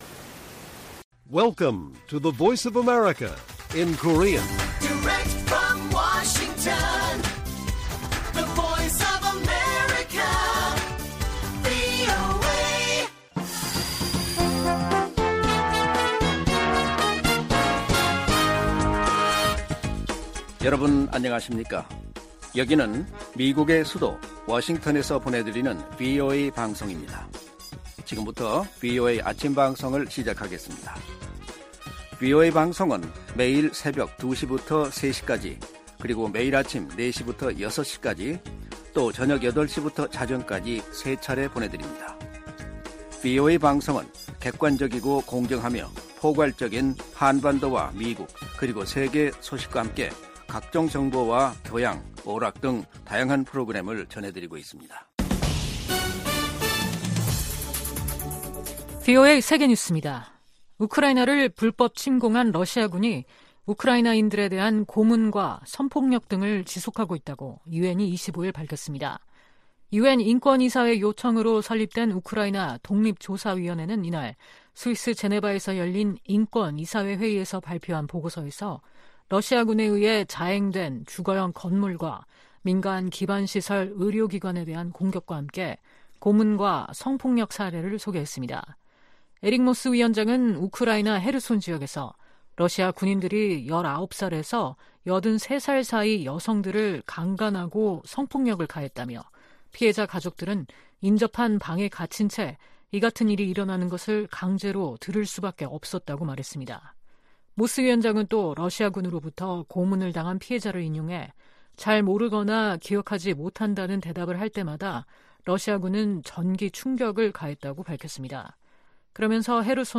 세계 뉴스와 함께 미국의 모든 것을 소개하는 '생방송 여기는 워싱턴입니다', 2023년 9월 27일 아침 방송입니다. '지구촌 오늘'에서는 제2회 미국-태평양도서국포럼(PIF) 정상회의에서 조 바이든 대통령이 기후변화에 관한 섬나라들의 우려에 귀를 기울이며 대규모 인프라 투자를 약속한 소식 전해드리고, '아메리카 나우'에서는 '셧다운' 시한이 5일 남은 가운데, 공화당 강경파의 반대로 한시적인 '임시지출안' 통과가 난항을 겪고 있는 이야기 살펴보겠습니다.